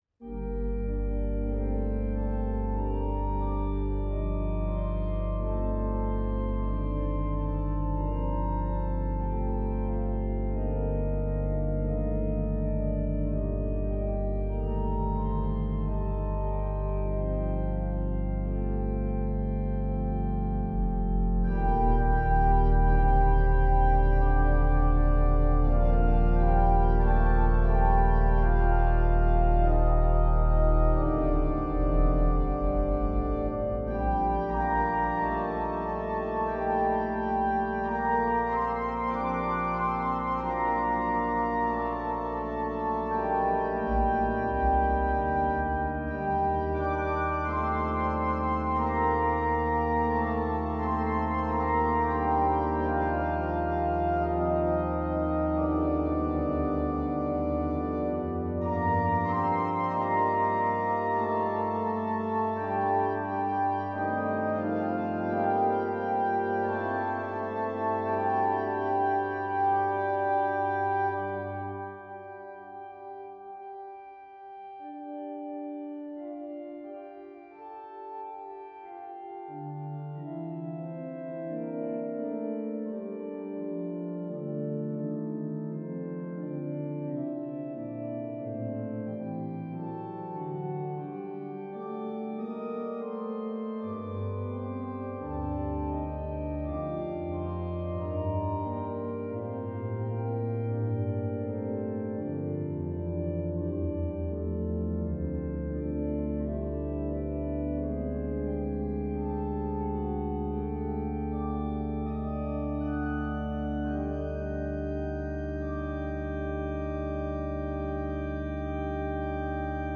organ Duration